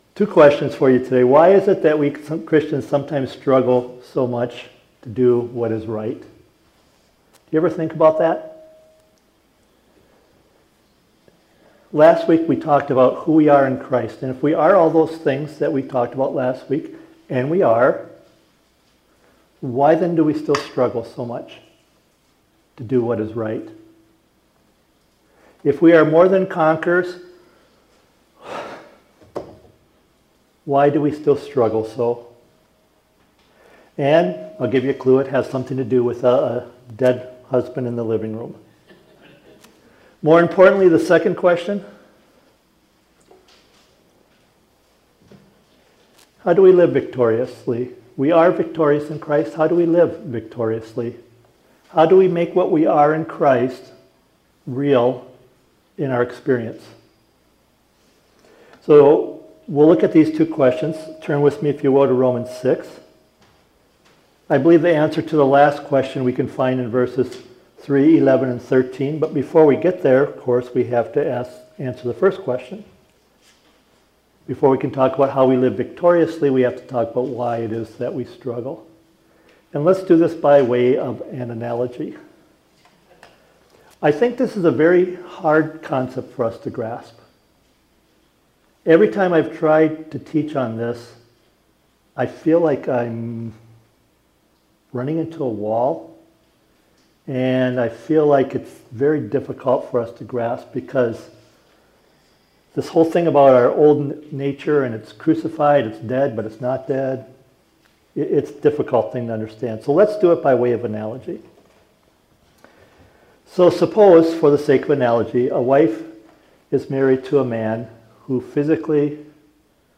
Sermons | Westview Primitive Methodist Church